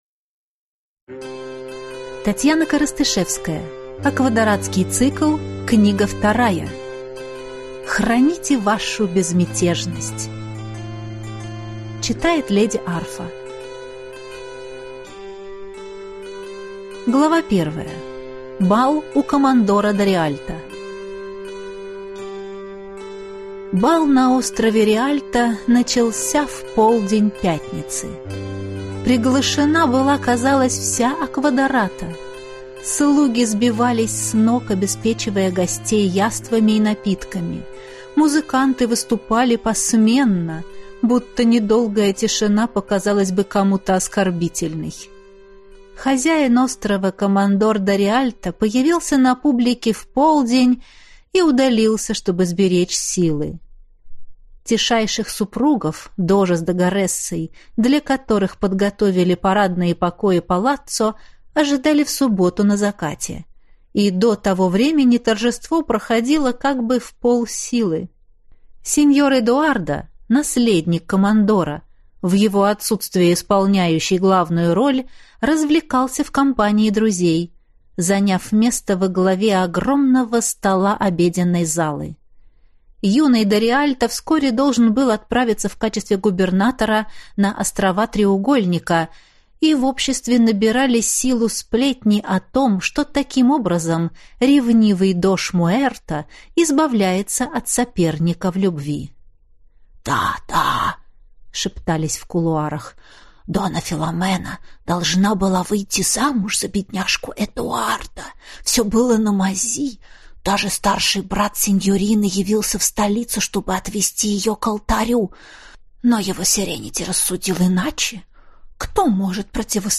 Аудиокнига Храните вашу безмятежность | Библиотека аудиокниг
Прослушать и бесплатно скачать фрагмент аудиокниги